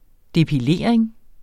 Udtale [ depiˈleˀɐ̯eŋ ]